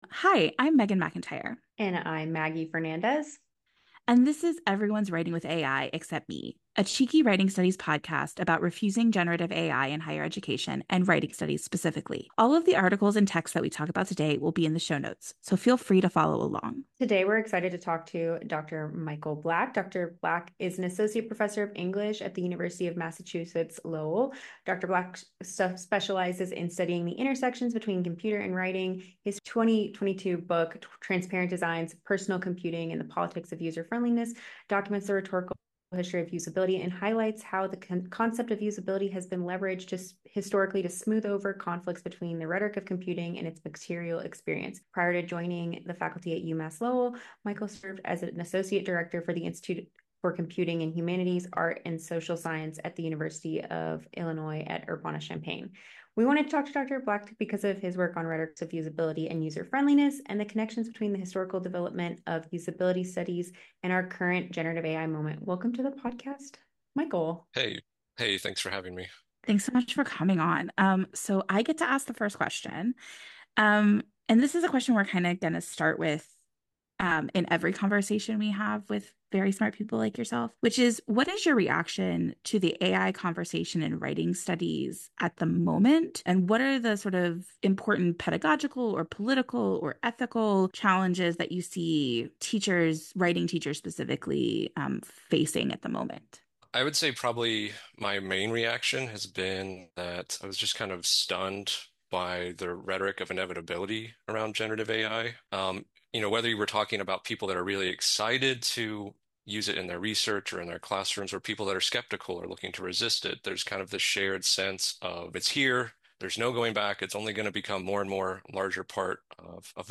Episode 4: An Interview